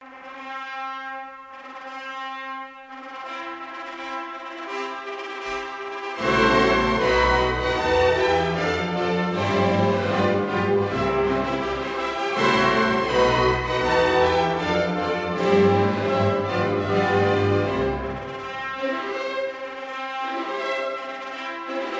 на свадебной церемонии